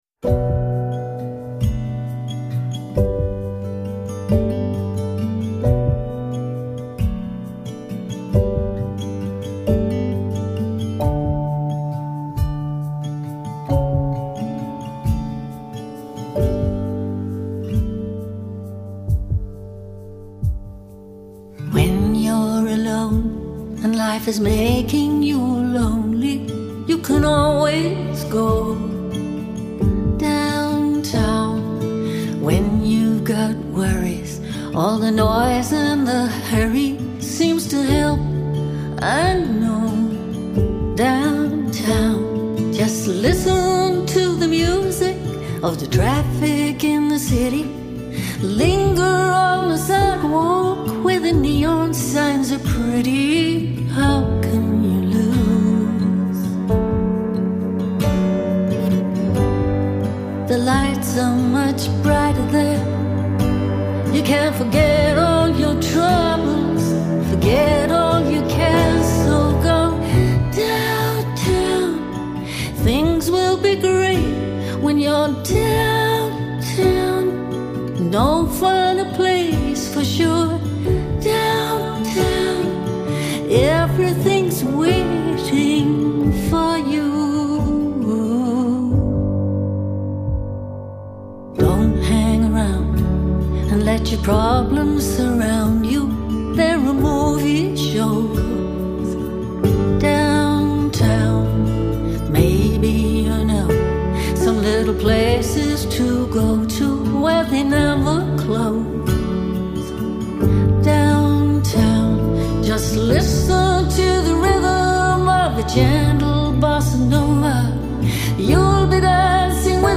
以優雅電子的節奏打開整張專輯的序章
呈現出一股溫柔清秀的氛圍